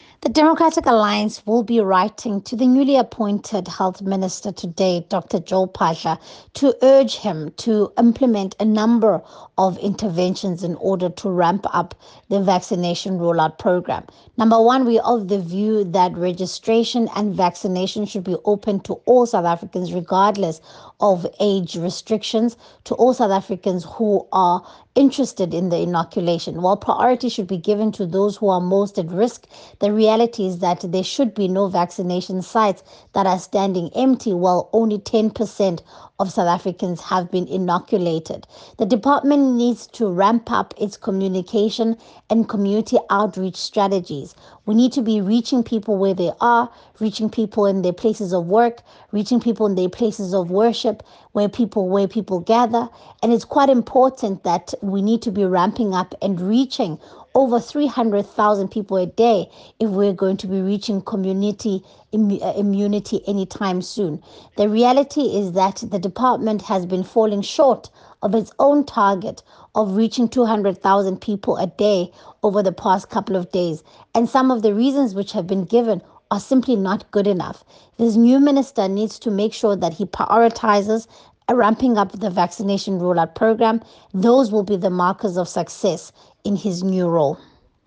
Issued by Siviwe Gwarube MP – DA Shadow Minister of Health
soundbite by Siviwe Gwarube MP.